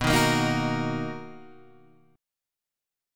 B Major 7th